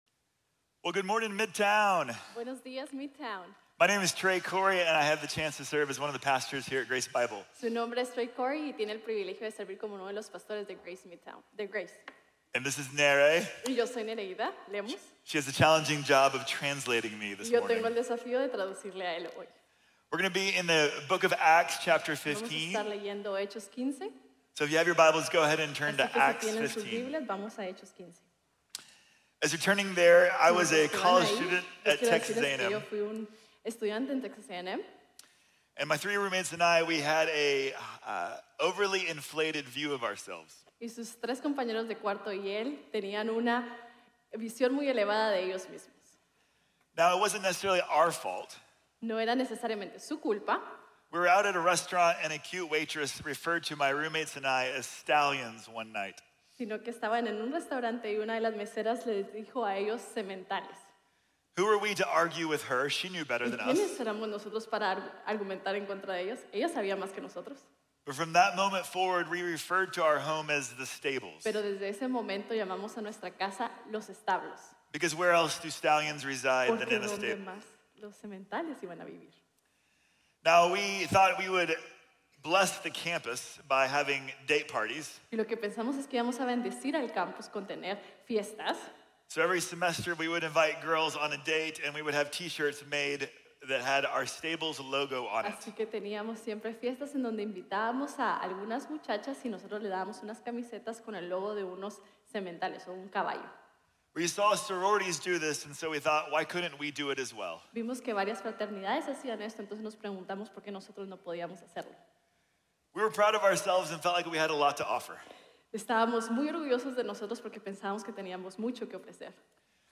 La invitación de la Gracia | Sermon | Grace Bible Church